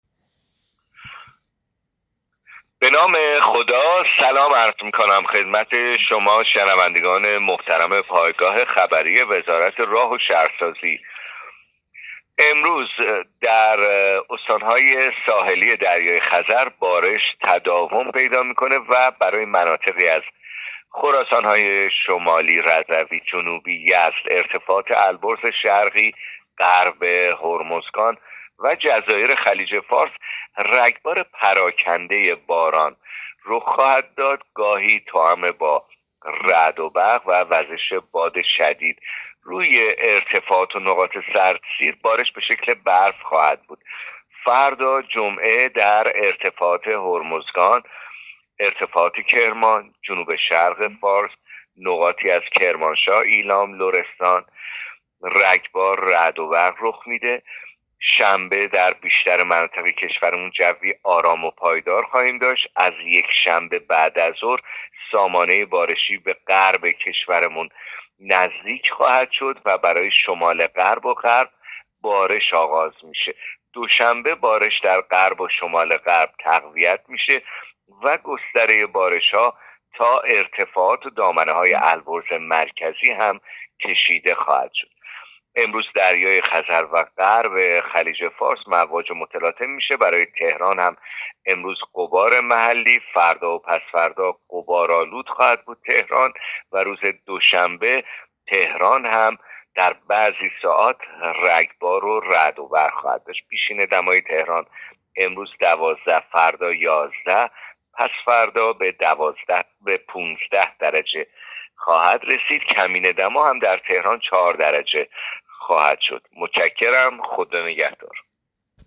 گزارش رادیو اینترنتی پایگاه‌ خبری از آخرین وضعیت آب‌وهوای ۱۳ آذر؛